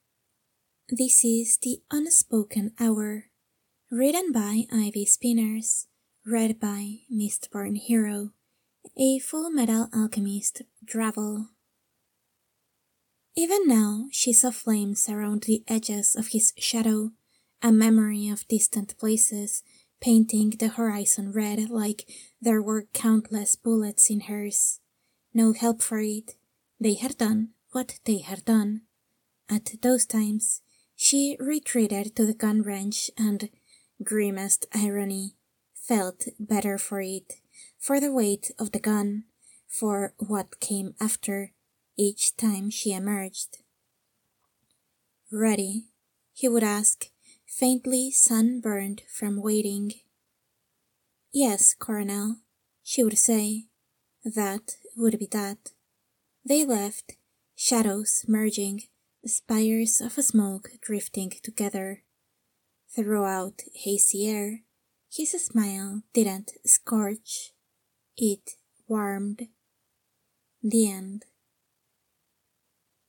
comment to the podficcer here read or comment on the text version here